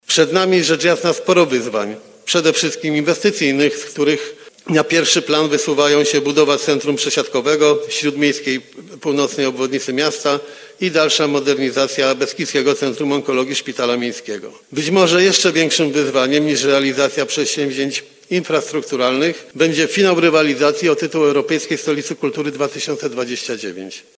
Słowa te padły podczas dzisiejszej, pierwszej sesji Rady Miejskiej w Bielsku-Białej kadencji 2024-2029.